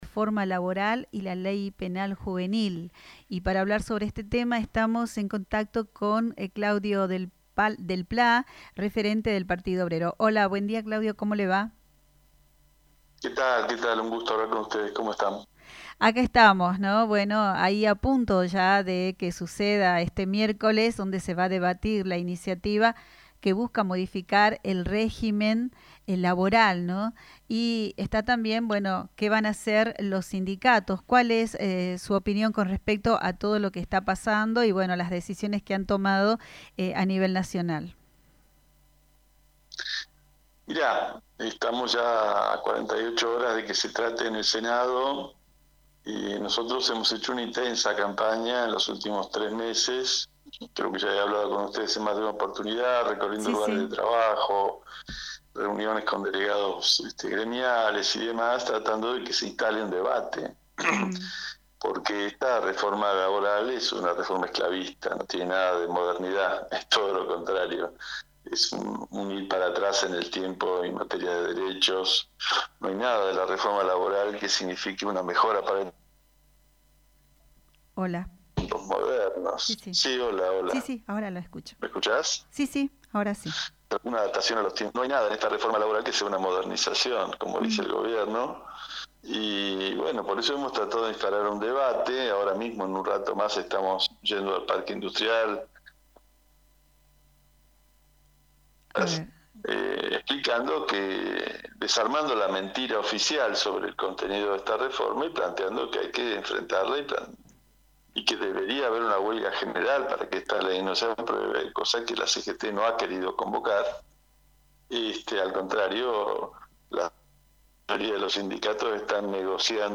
Uno de los ejes más fuertes de la entrevista es la crítica a las centrales sindicales.